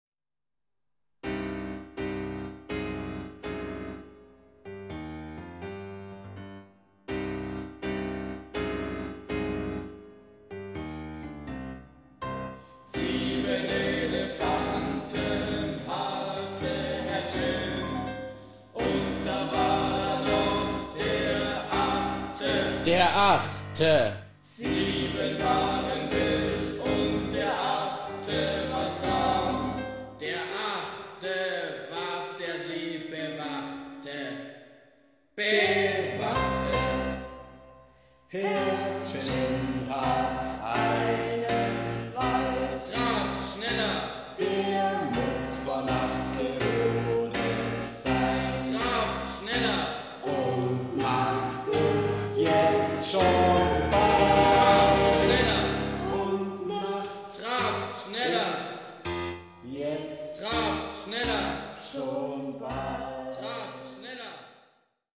klavierlieder